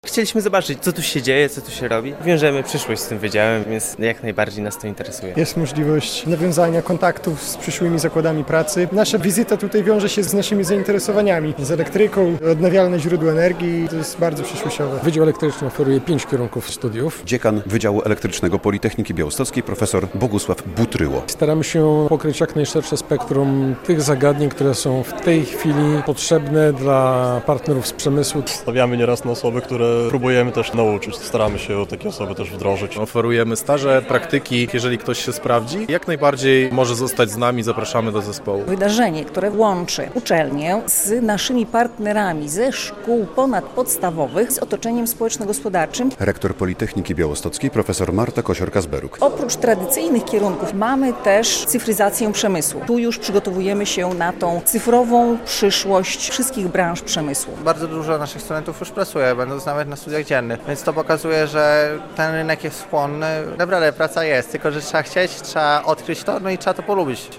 Dzień Elektryka - relacja